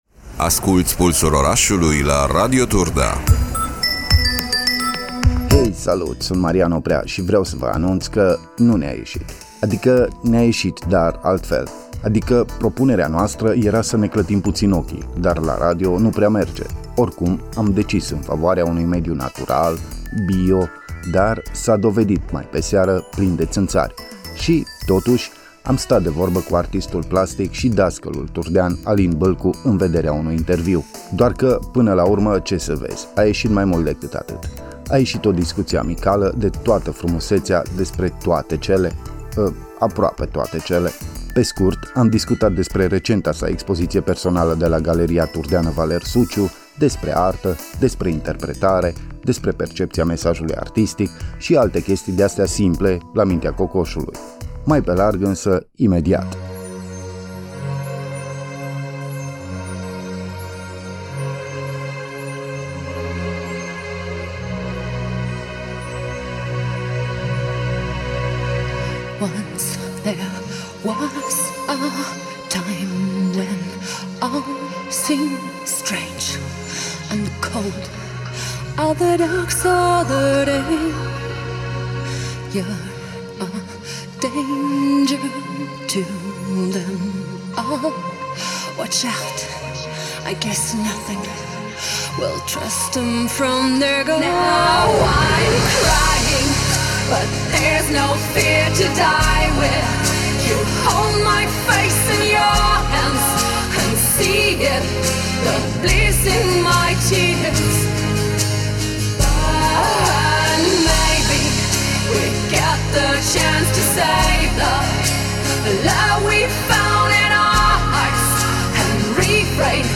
Ne-a ieşit o discuţie amicala de toată frumuseţea.